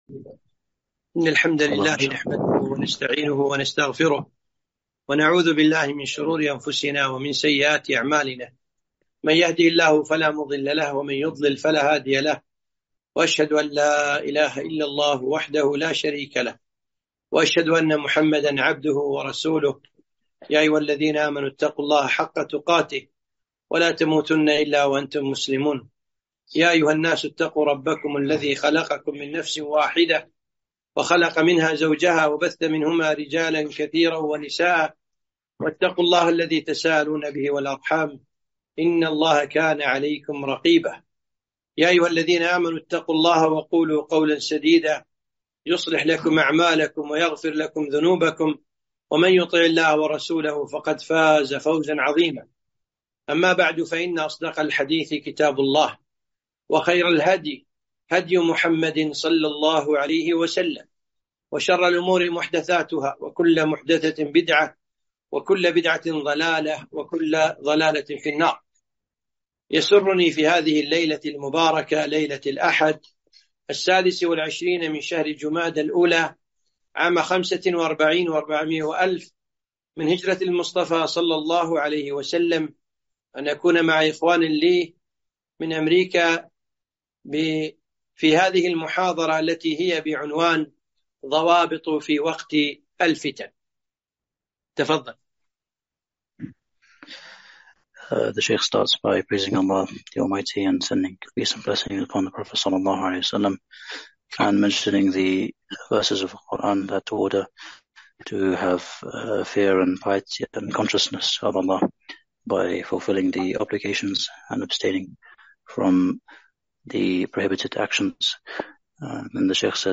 محاضرة - إرشادات في أوقات المحاكمات و المحن (مترجمة للإنجليزية)